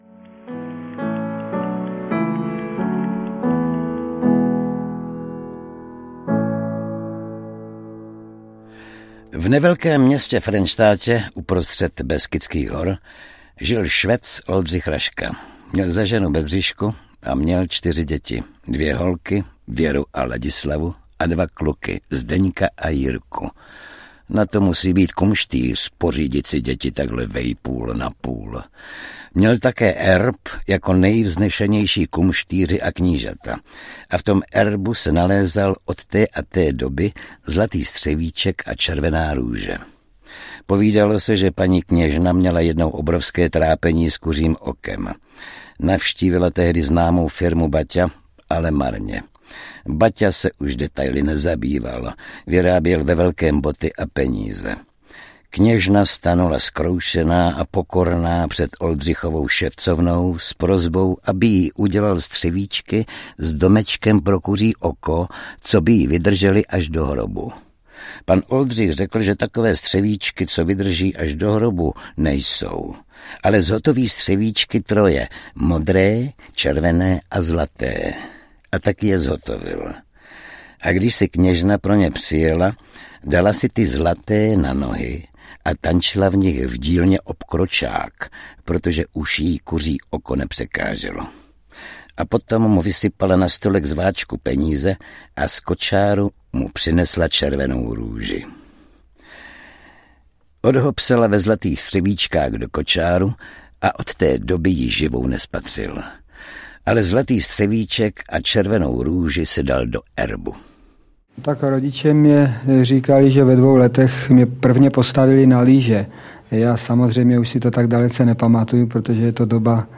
Pohádka o Raškovi audiokniha
Ukázka z knihy
• InterpretOldřich Kaiser